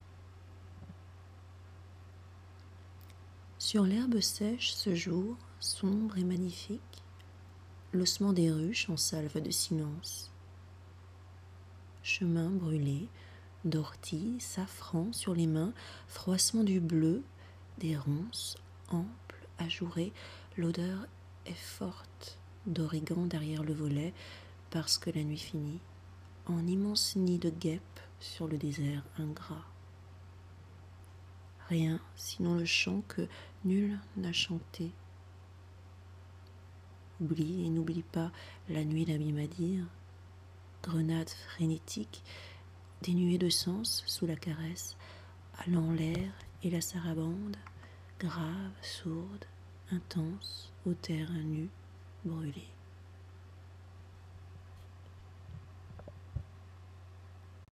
프랑스어로 낭독한 시 (미발표).